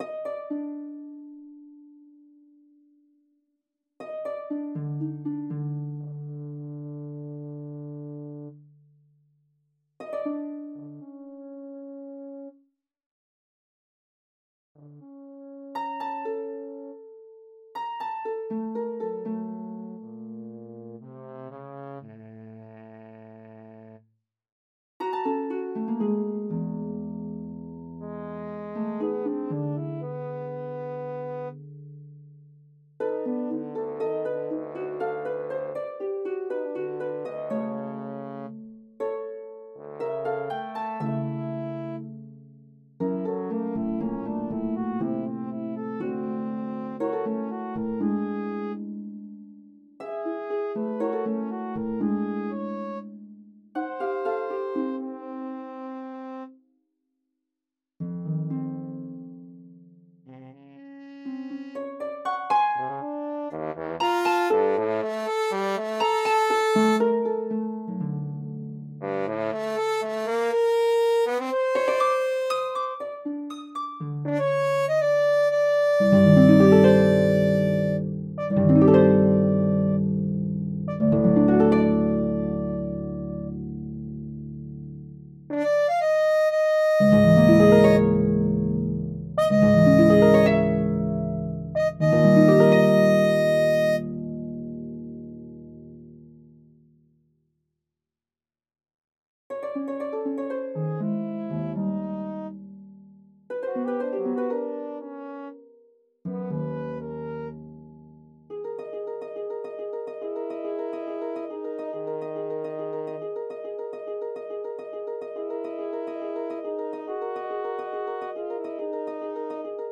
for Harp and Horn